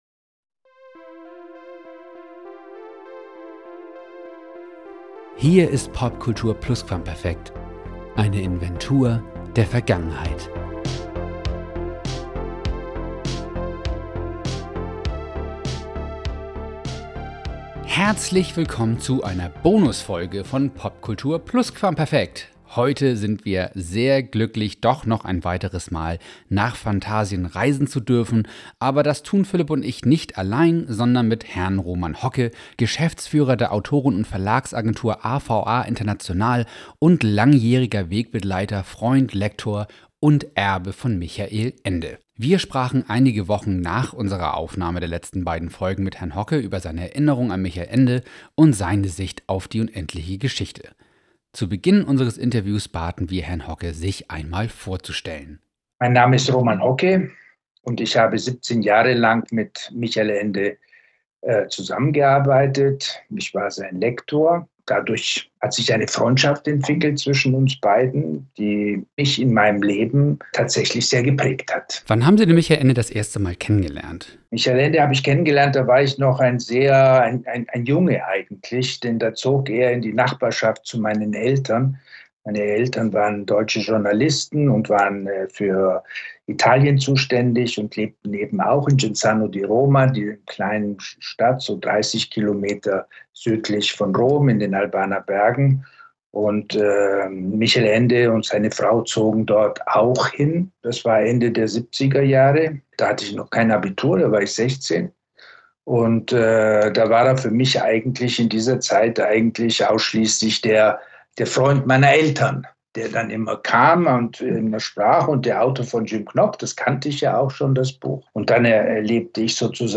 Die Unendliche Geschichte - Teil 3: Das Interview